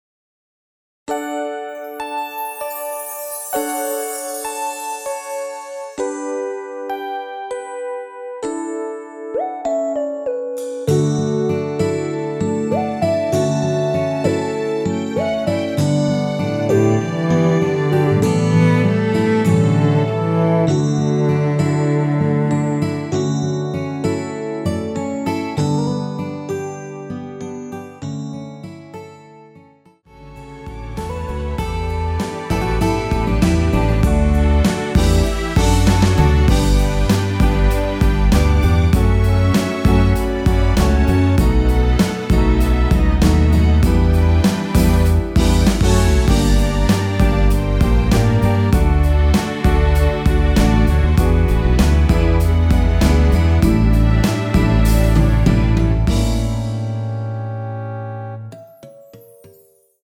원키에서(+1)올린 MR입니다.
D
앞부분30초, 뒷부분30초씩 편집해서 올려 드리고 있습니다.
중간에 음이 끈어지고 다시 나오는 이유는